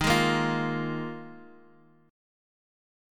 Ebsus2sus4 Chord